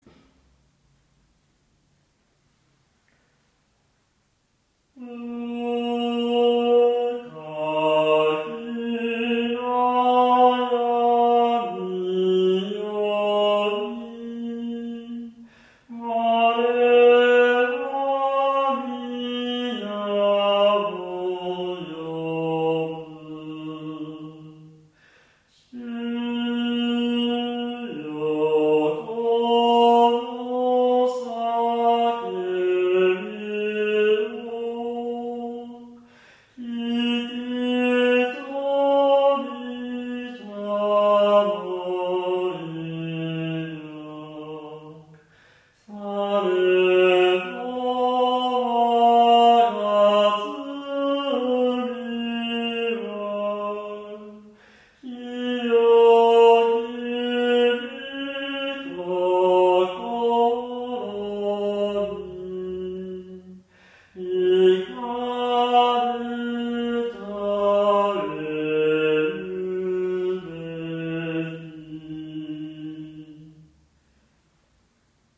下降形で少しミーントーンを織り混ぜています。２行目最初と３行目後半で音程が不安定に。
もともと音律の並びが破綻をきたしていますが、上行音形で開放するべきところは広く取ります。